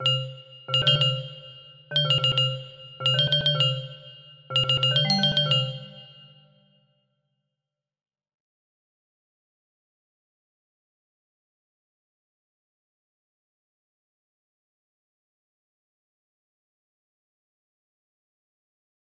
effect 4 sound. music box melody